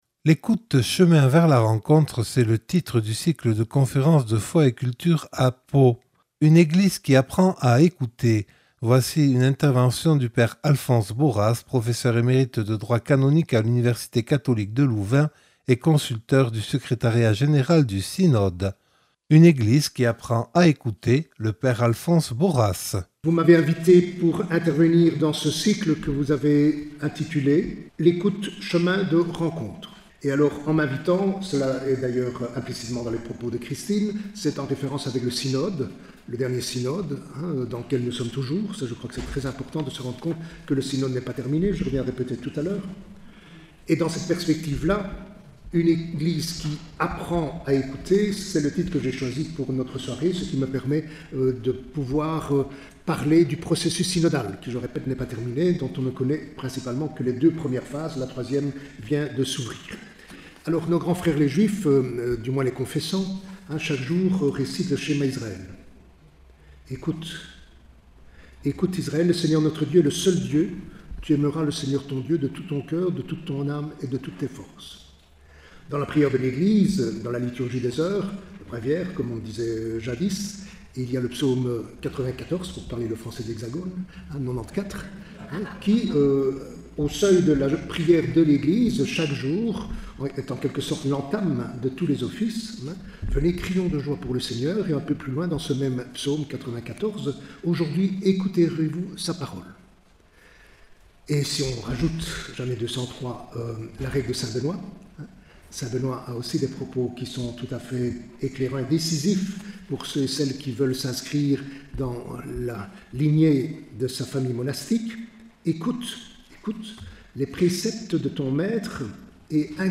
(Enregistré le 11 avril 2025 dans le cadre du cycle « Foi et Culture » à Pau).